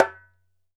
ASHIKO 4 0KR.wav